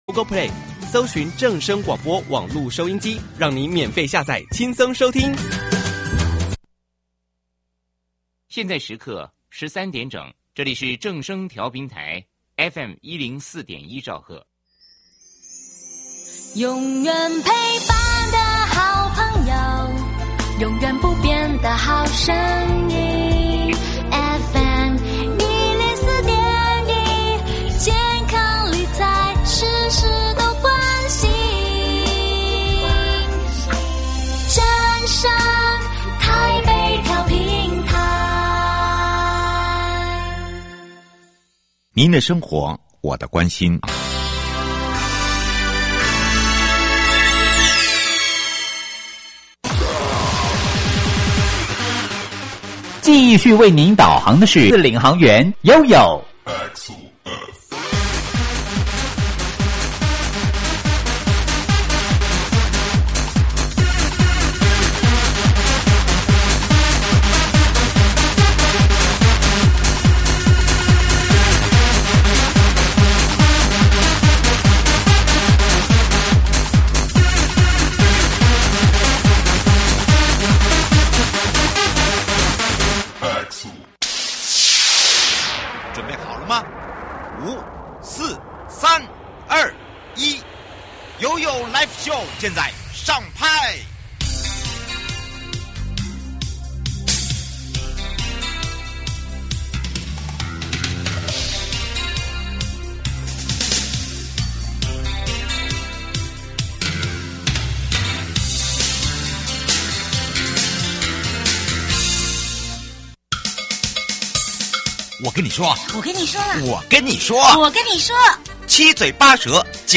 受訪者： 營建你我他 快樂平安行~七嘴八舌講清楚~樂活街道自在同行!